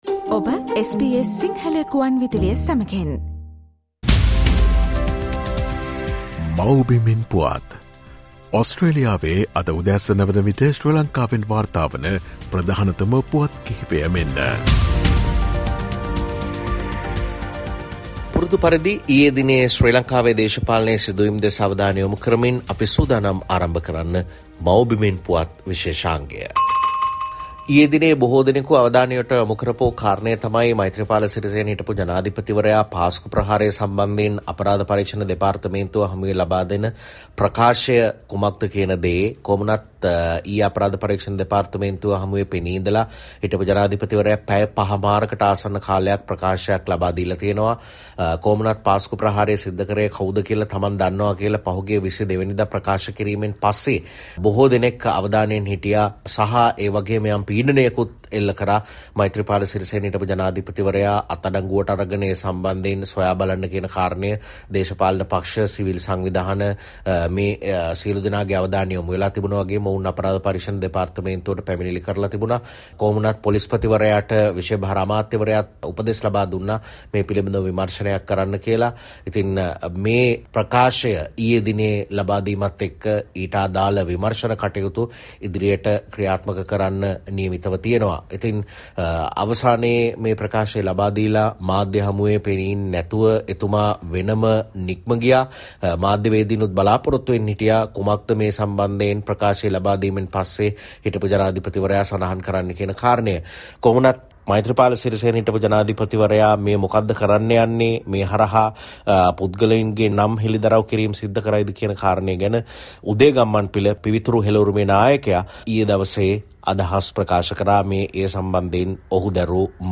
SBS Sinhala featuring the latest news reported from Sri Lanka - Mawbimen Puwath